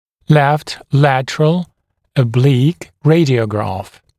[left ‘lætərəl ə’bliːk][лэфт ‘лэтэрэл э’бли:к]левый боковой наклонный снимок